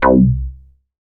MoogResFilt 010.WAV